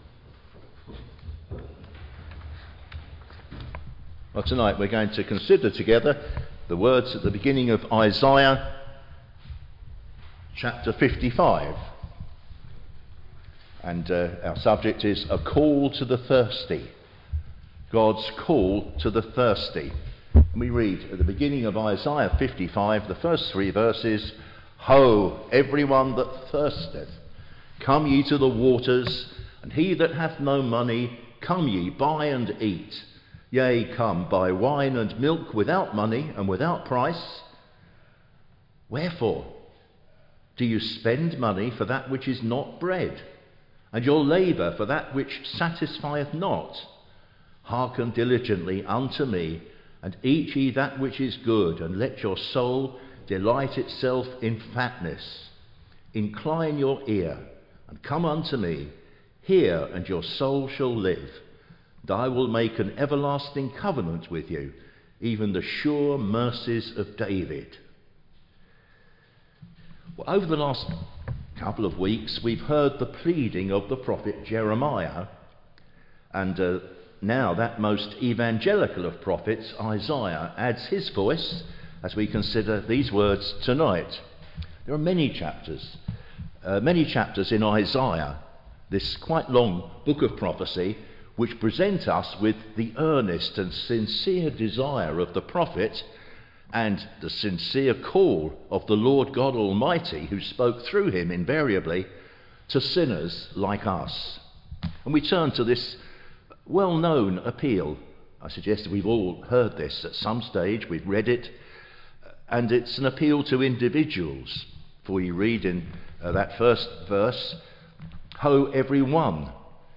Teaching and Gospel Sermons on Isaiah